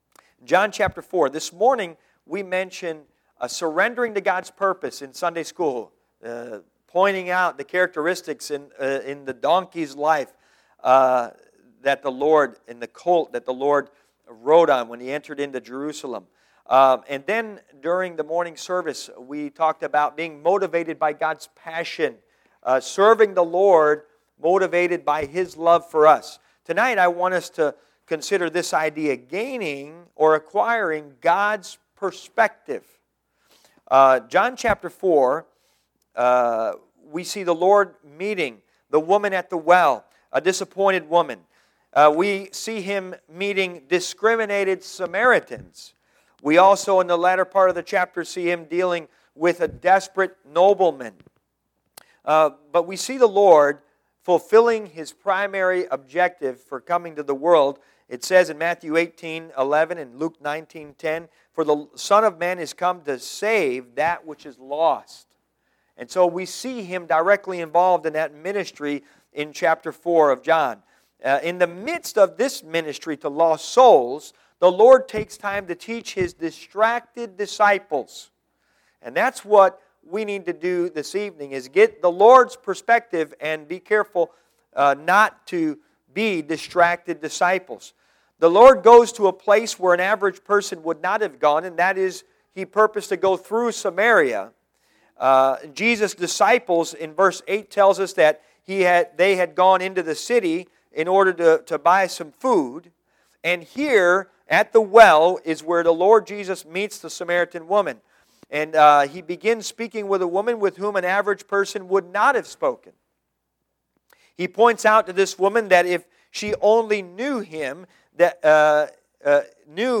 John Passage: John 4:10-38 Service Type: Sunday PM Bible Text